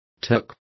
Complete with pronunciation of the translation of turks.